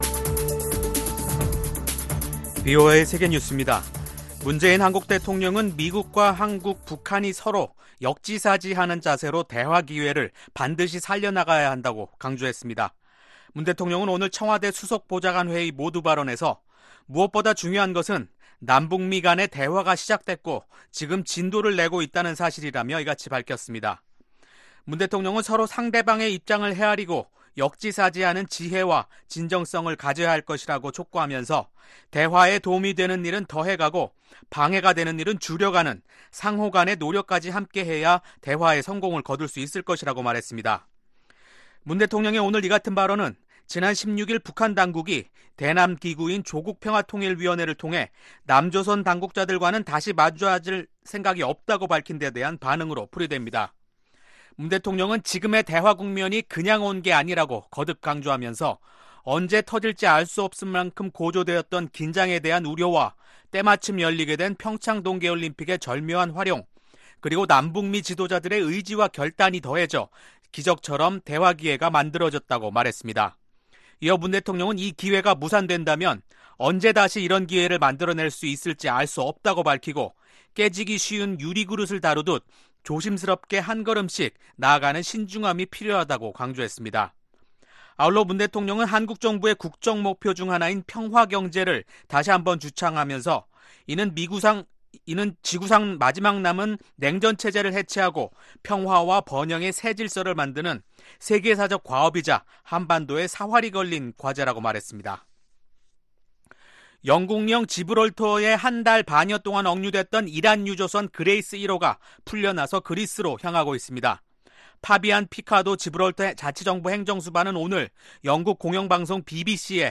VOA 한국어 간판 뉴스 프로그램 '뉴스 투데이', 2019년 8월 15일 3부 방송입니다. 스티븐 비건 미 국무부 대북특별대표가 이번주 일본과 한국을 방문합니다. 국무부는 북한과 대화할 준비가 돼 있다고 밝혔습니다.